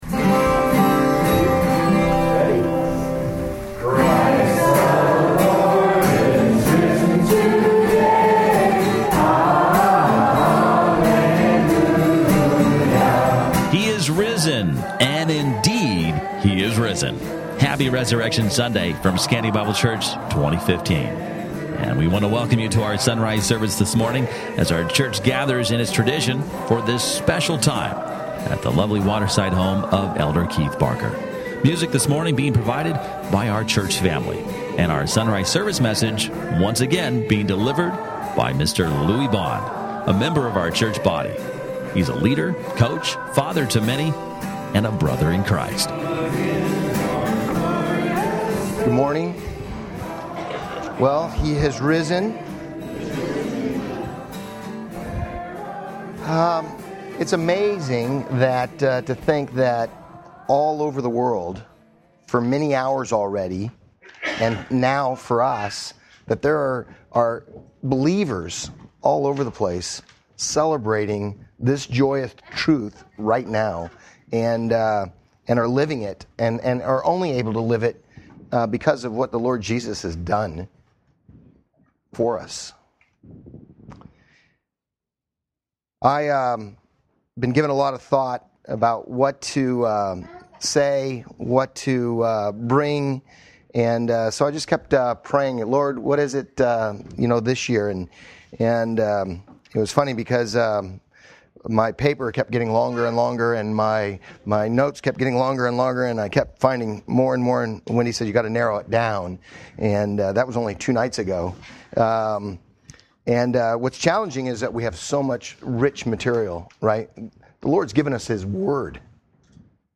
Sermon Notes